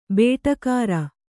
♪ bēṭakāra